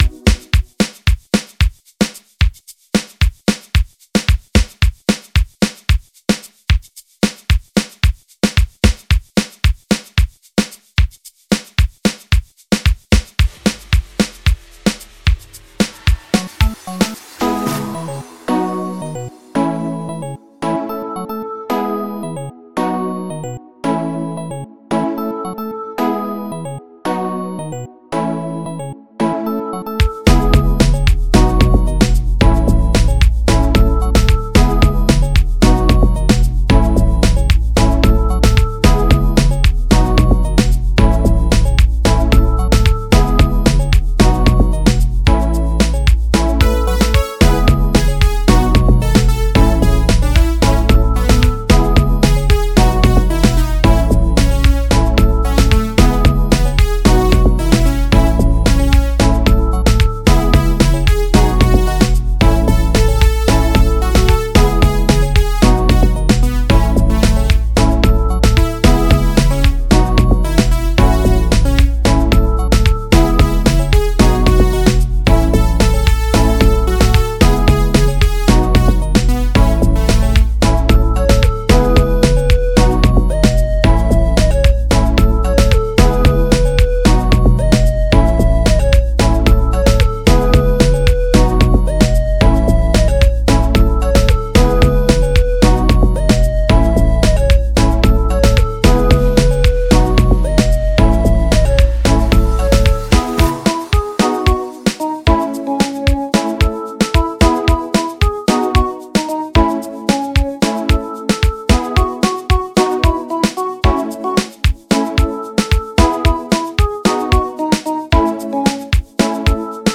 05:09 Genre : Amapiano Size